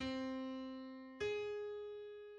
En opadgående lille sekst kan for eksempel gå fra c1 til a♭1: